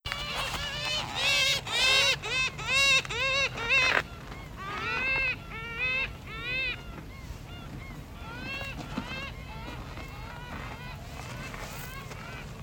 トウゾクカモメ
【分類】 チドリ目 トウゾクカモメ科 トウゾクカモメ属 トウゾクカモメ 【分布】北海道(旅鳥)、本州(旅鳥)、四国(旅鳥)、九州(旅鳥)、沖縄(旅鳥) 【生息環境】海上に生息 【全長】51cm 【主な食べ物】魚、動物の死体 【鳴き声】地鳴き 【聞きなし】「キョッキョッキョッ」「ケェェェー」